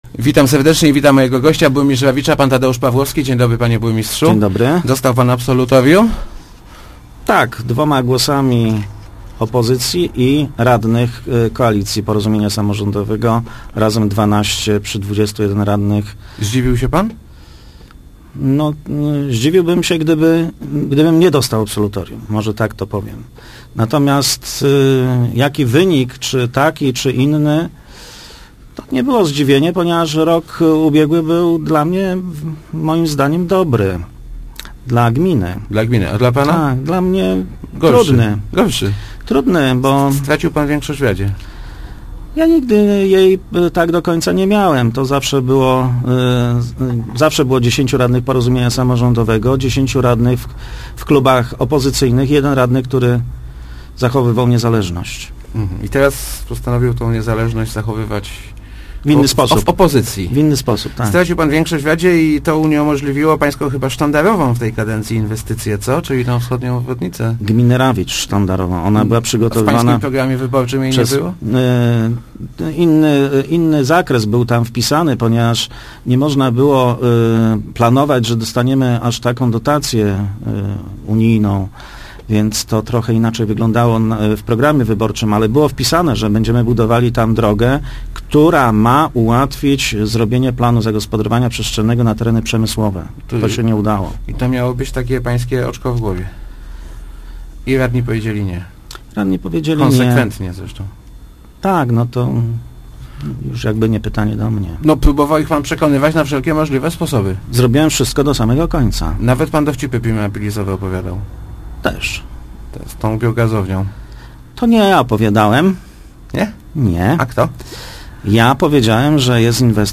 tpawlowski80.jpgMedia i radni mnie nie zrozumieli – mówił w Rozmowach Elki burmistrz Tadeusz Pawłowski, odnosząc się do sprawy budowy biogazowi w Rawiczu.